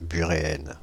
Ääntäminen
Ääntäminen France (Île-de-France): IPA: /by.ʁe.ɛn/ Haettu sana löytyi näillä lähdekielillä: ranska Käännöksiä ei löytynyt valitulle kohdekielelle.